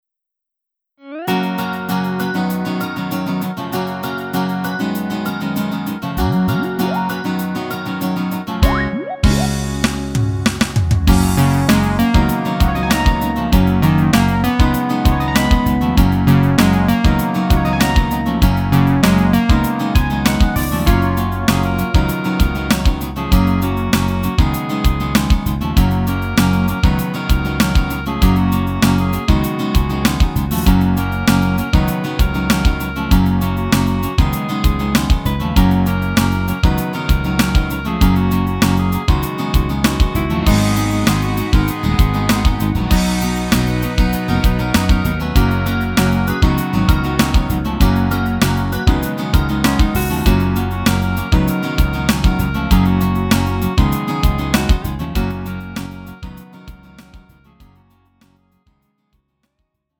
음정 -1키 4:01
장르 pop 구분 Lite MR